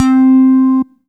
NINTYBASS C5.wav